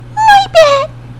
Audio / SE / Cries / NOIBAT.mp3